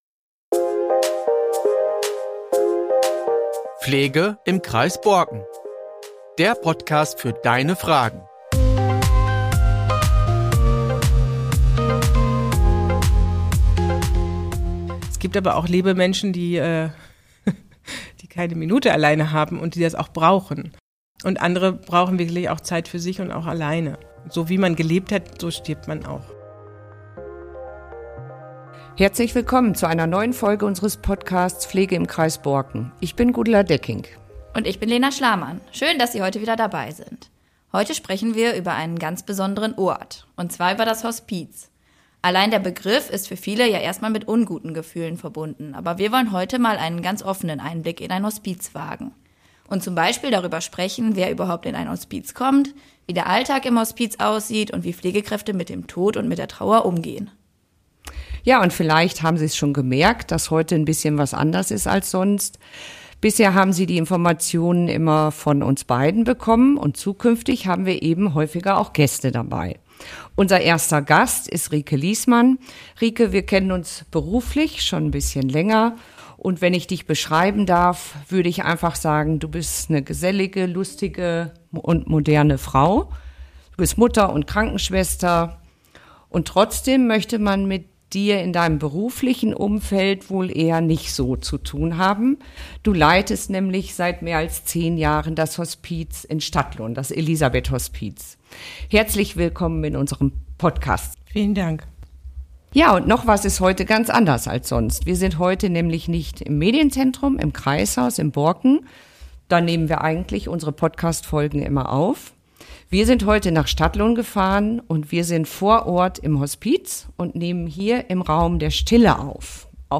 Neben den menschlich berührenden Aspekten geht es auch um organisatorische und finanzielle Rahmenbedingungen, die für den Betrieb eines Hospizes unverzichtbar sind. Das Gespräch zeigt, wie wertvoll die Hospize für unsere Region sind – und in welcher Weise sie das pflegerische Angebot von Pflegediensten, Wohngemeinschaften und Pflegeheimen ergänzen. Ein offenes und wohltuendes Gespräch mit vielen interessanten Aspekten rund um die Hospizarbeit.